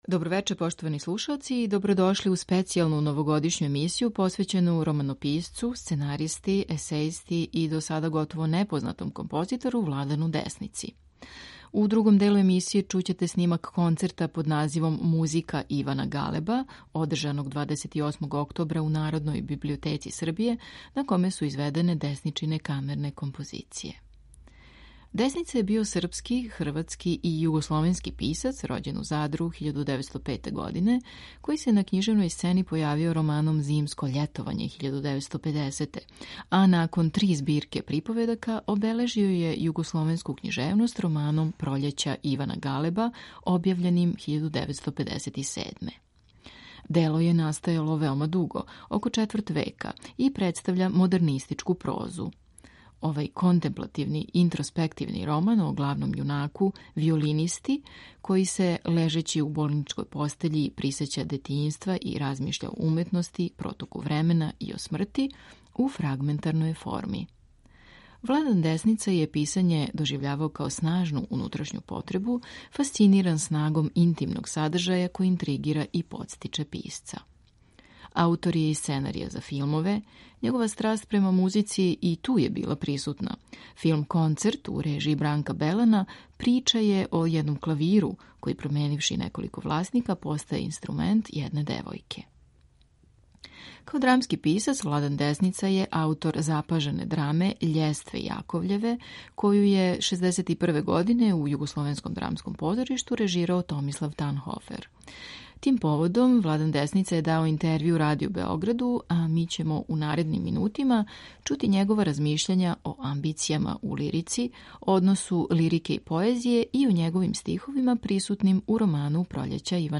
Новогодшњи специјал 1. јануара посвећујемо књижевнику Владану Десници, у чију част је 28. октобра 2021. у Народној библиотеци Србије одржан концерт на коме су изведене до сада непознате камерне композиције овог ствараоца.
У првом делу емисије чућете Владана Десницу који је у интервјуу за Радио Београд 1961. године читао своје стихове.
сопран
тенор
виолина
клавир